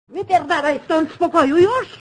Worms speechbanks
fire.wav